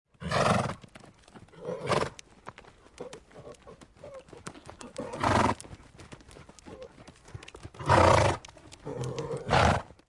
Horse Snort Téléchargement d'Effet Sonore
Horse Snort Bouton sonore